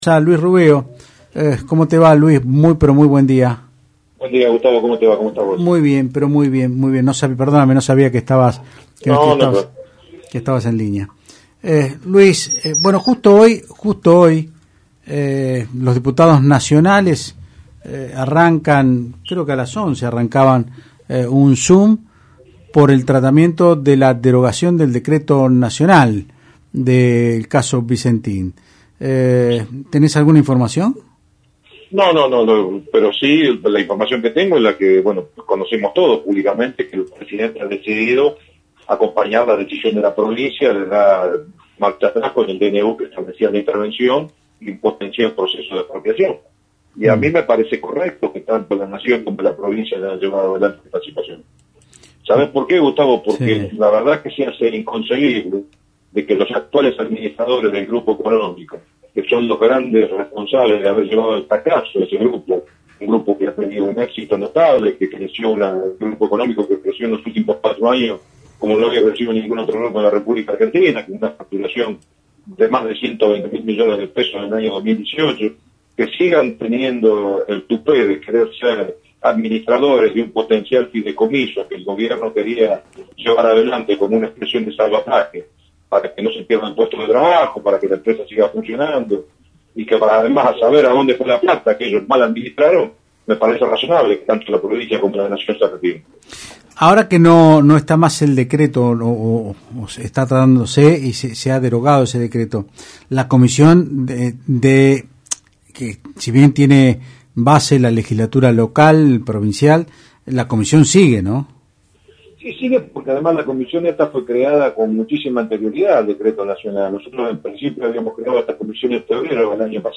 El diputado provincial Luis Rubeo dijo en Otros Ámbitos (Del Plata Rosario 93.5) que los directivos de Vicentin quieren entorpecer el proceso de salvataje instrumentado por la Provincia, porque tienen como idea ganar tiempo, para poder ocultar el vaciamiento que se viene llevando adelante por parte de estos directivos, que inevitablemente va a terminar llevando a la quiebra del grupo.